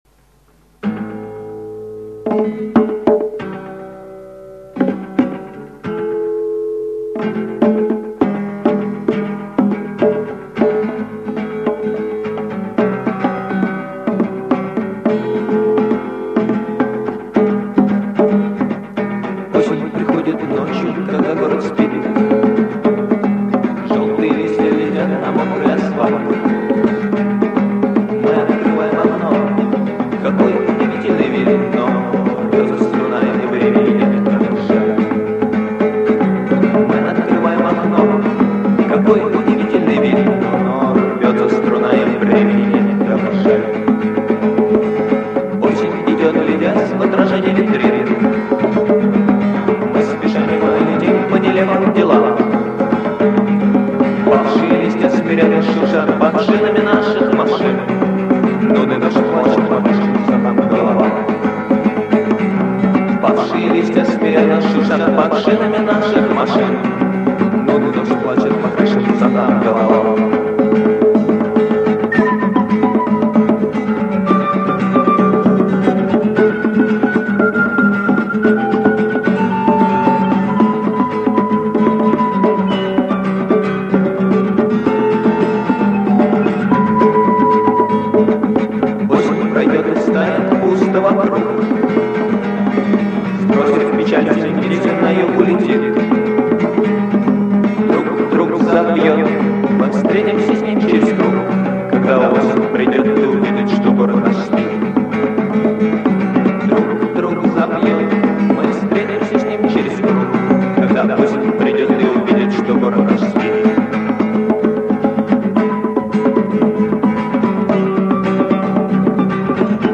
/с гитарой, эхом, бонгами и свирелькой/